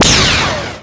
fire_photon5.wav